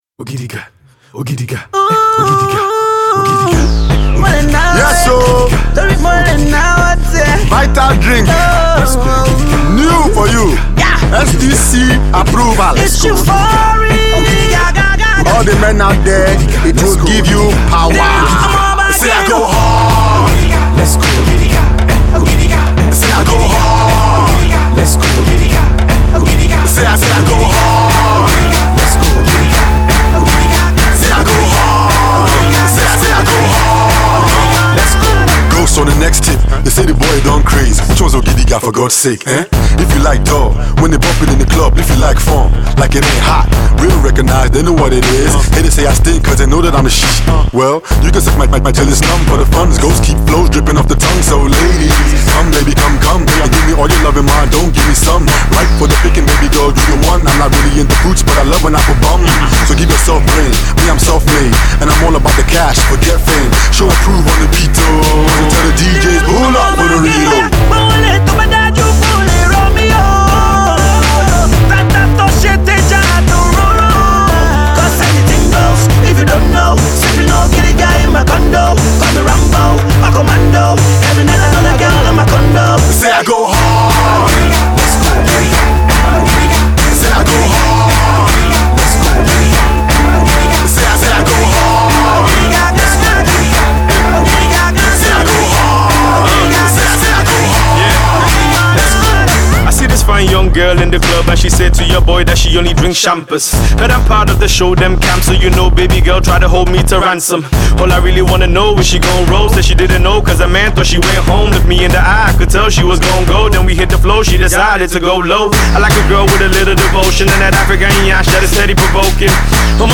rap duo
A club/radio ready single